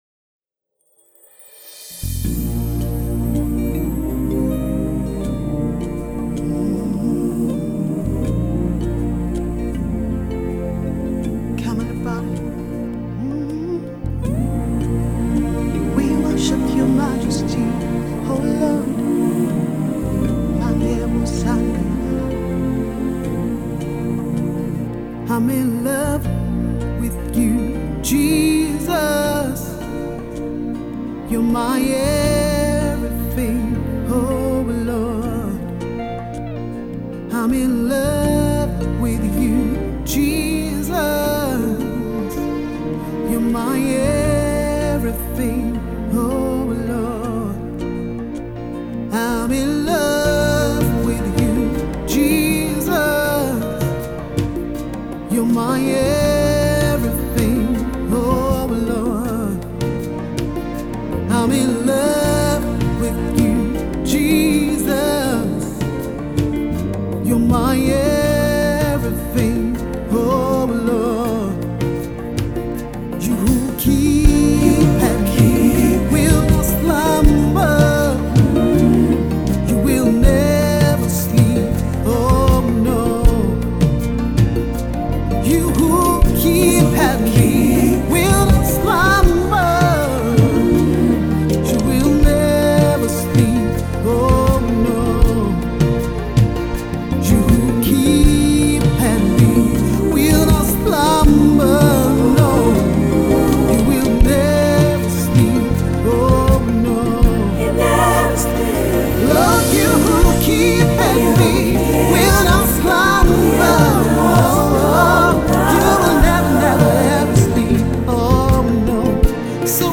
heartfelt worship single